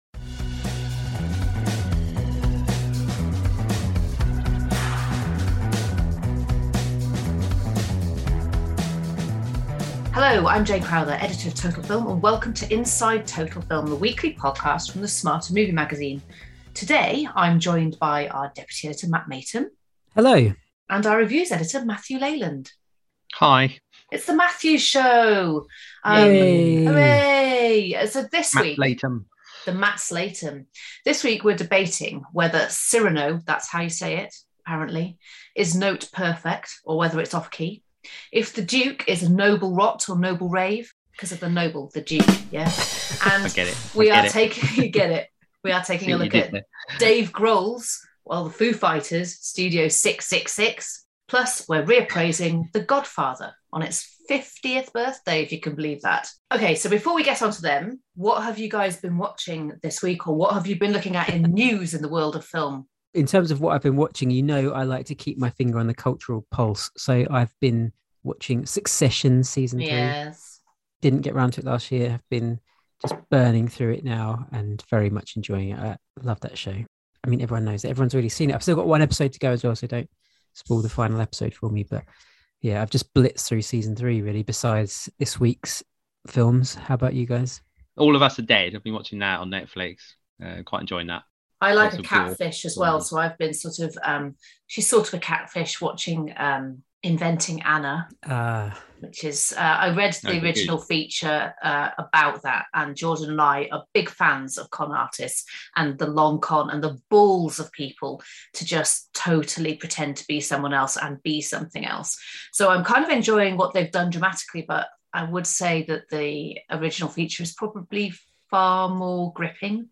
Plus the usual terrible accents, cat interruptions and awards bemusement.